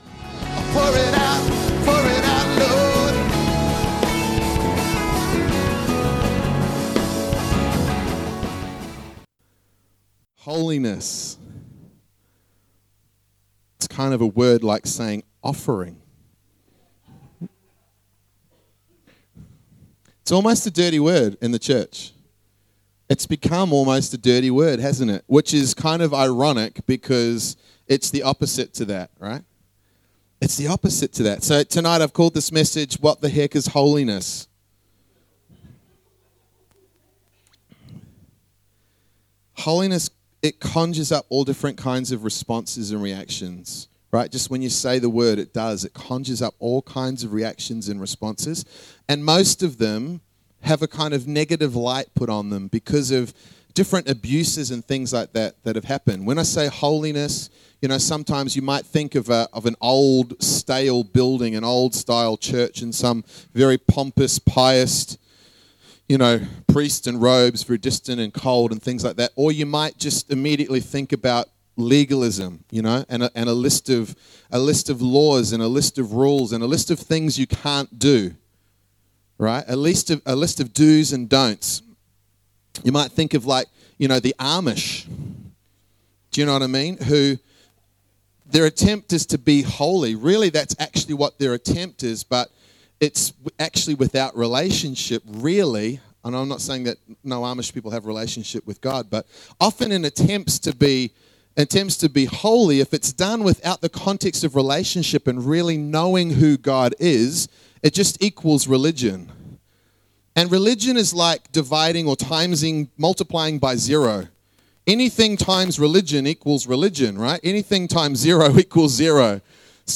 Not sure why, but when I did the sermon on Holiness from May 2018 came up.